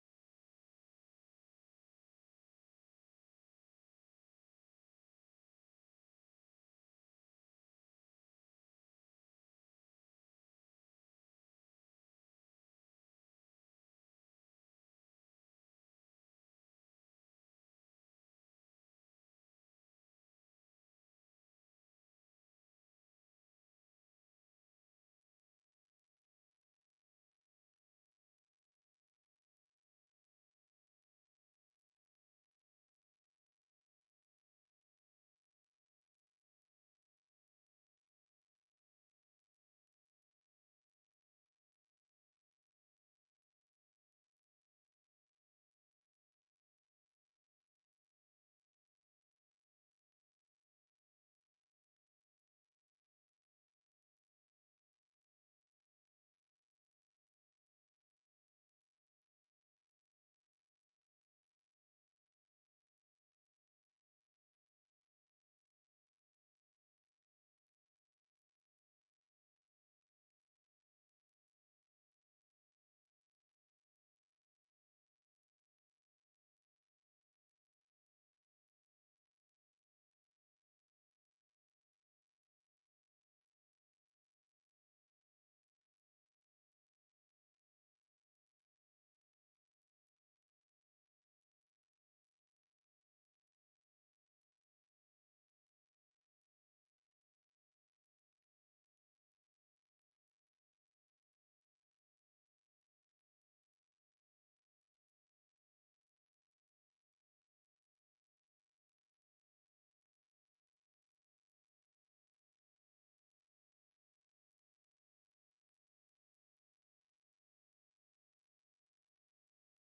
Budget, Personnel and Nominating Committee Meeting